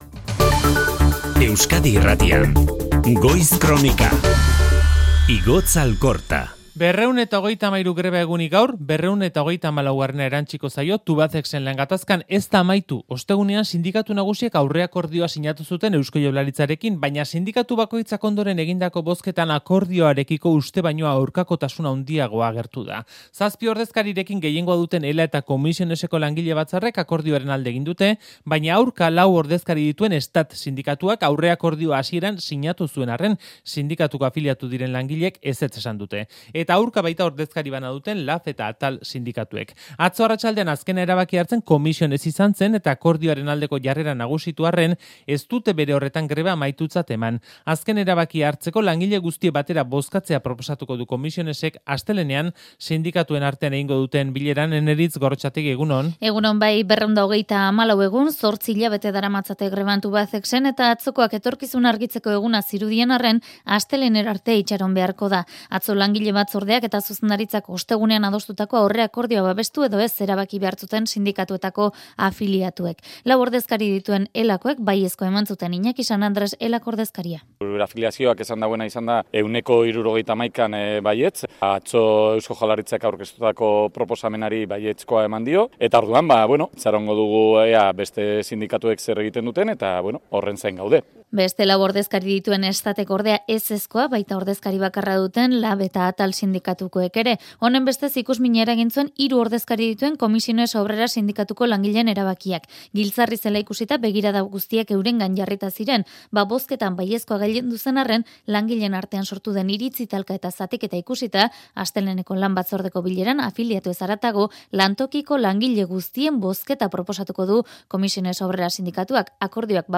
Ander Añibarro, Laudioko alkatea: Berebiziko kezka eragiten digu langileen arteko talkak izateak